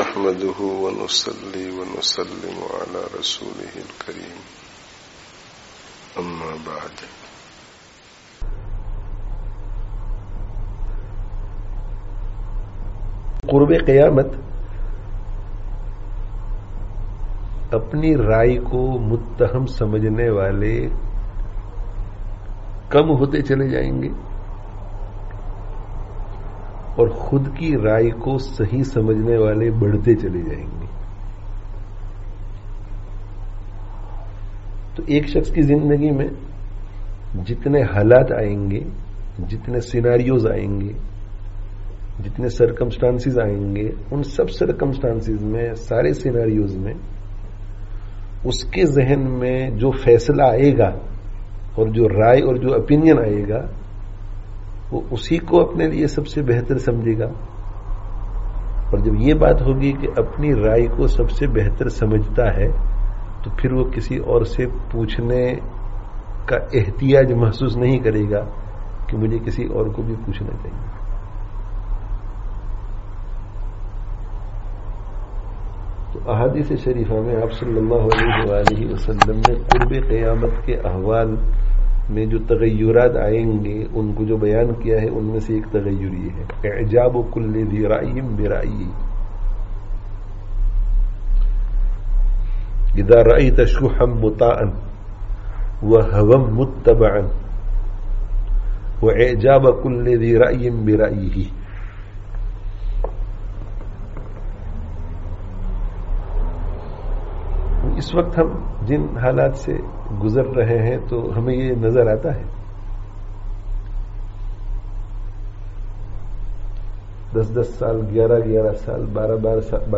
[Informal Majlis] Har Shakhs ko Apnī Rā'ī Achchī Ma'lūm Hotī hai (01/09/20)